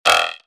drum & bass samples
Frog 18 E